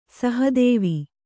♪ saha dēvi